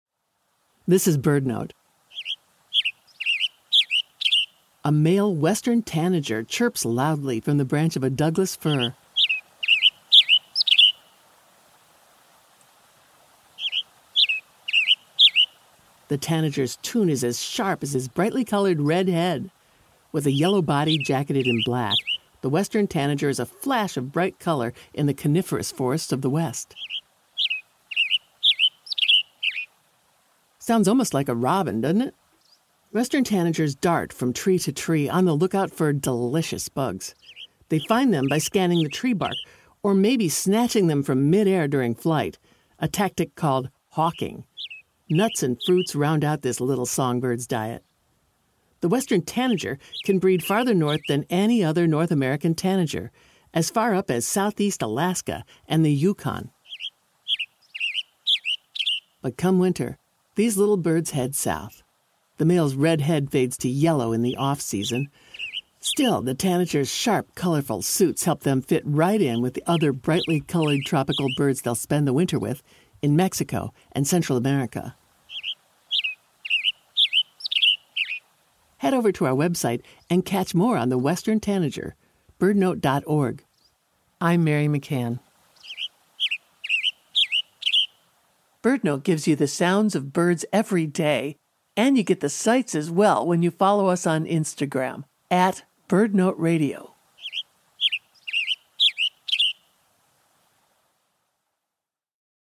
A Great Kiskadee calls.
Deep in a thicket, Black-hooded Antshrikes call.
In the evening, the sounds of cicadas give way to the sounds of crickets and a chorus of Marine Toads pours forth like no other.